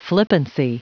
Prononciation du mot flippancy en anglais (fichier audio)
Prononciation du mot : flippancy